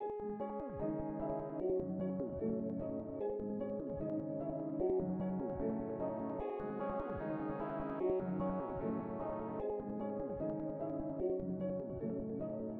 [SMG] Reckless 150bpm.wav